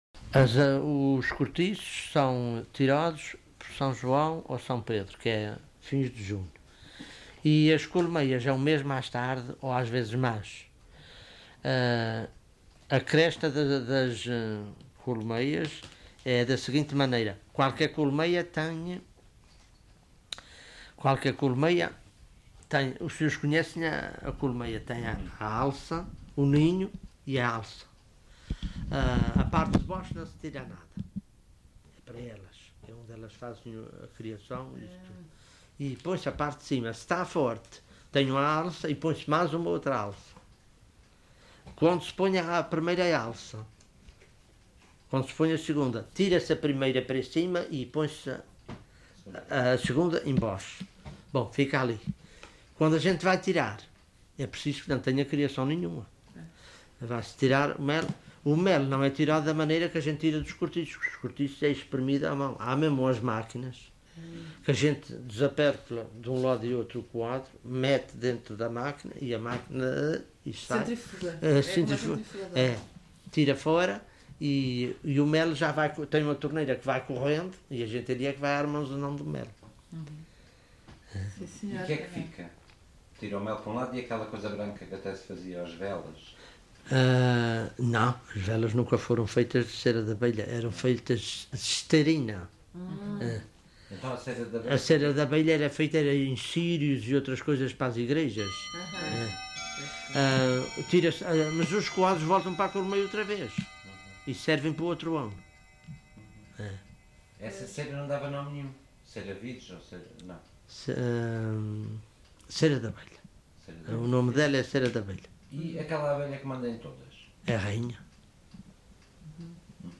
LocalidadePedras de São Pedro (Vila do Porto, Ponta Delgada)